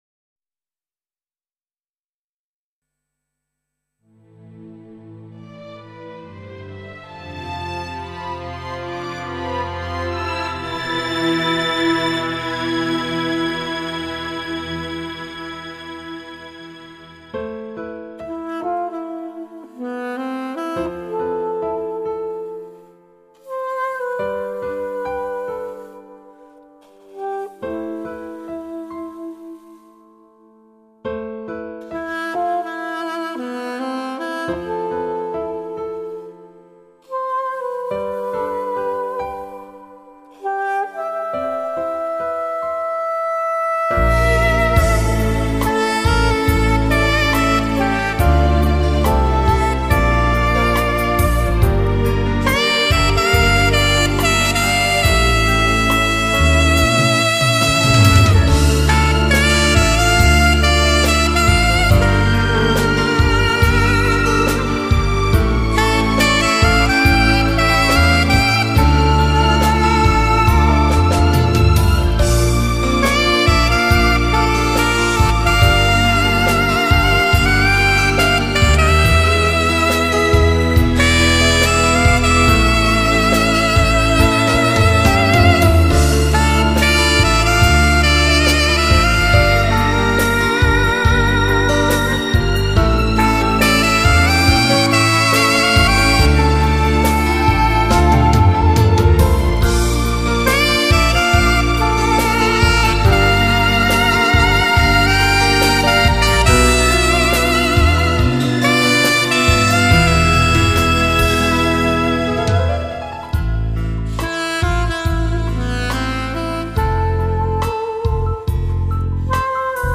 [1/18/2008]情调萨克斯---浅闻冬夜暗香